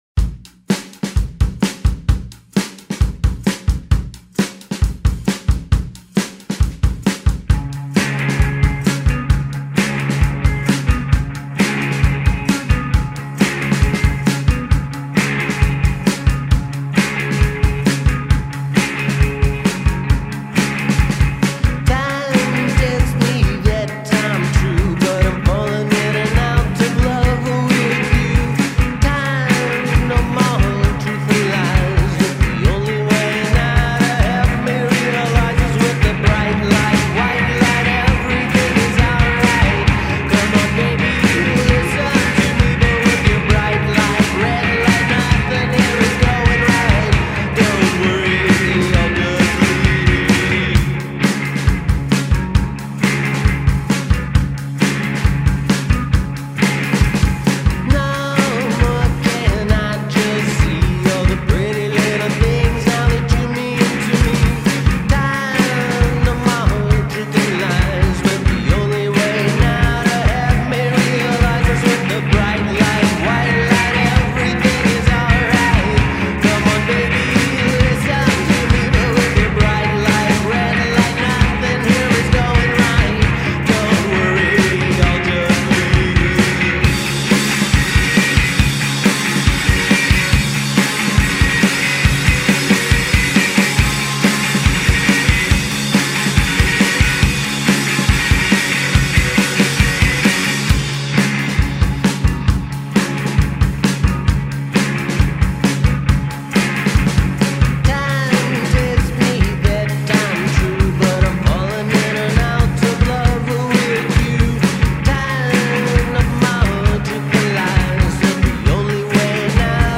rock duo